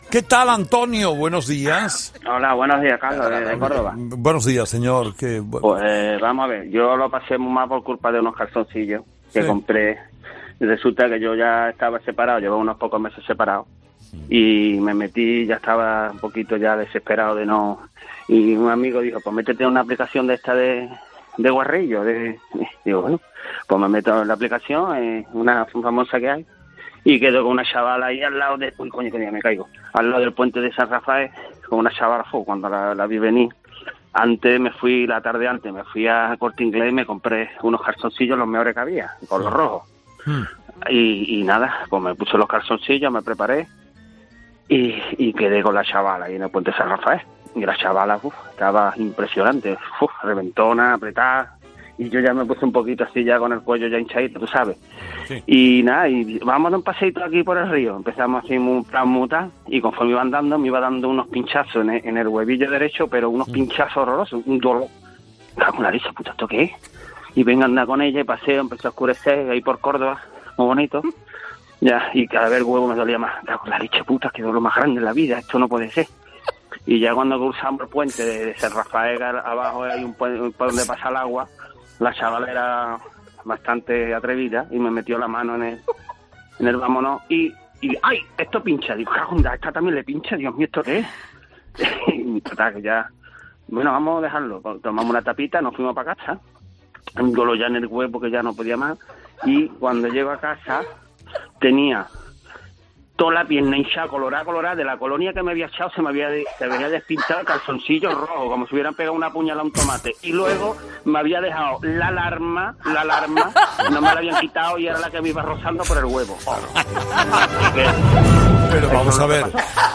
Estas fueron algunas de las preguntas que se formularon para que contasen los fósforos sus peculiares anécdotas.